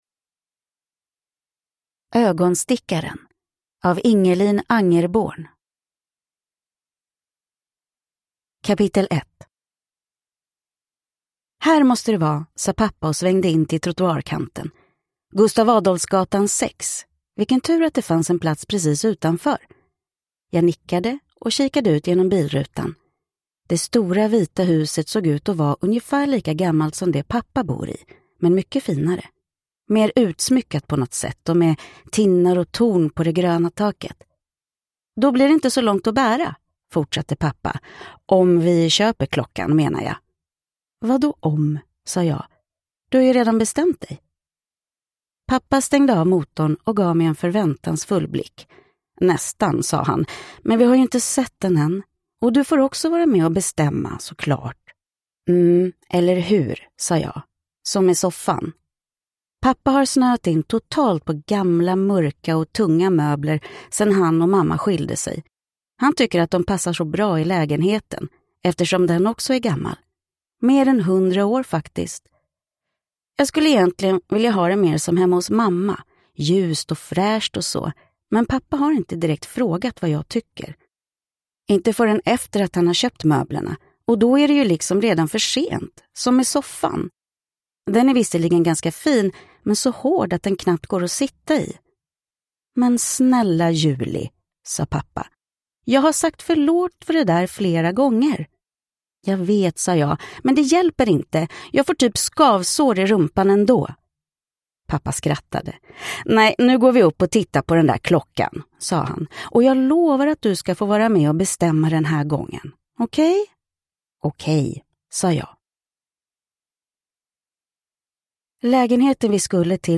Ögonstickaren – Ljudbok